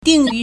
定于 (定於) dìngyú
ding4yu2.mp3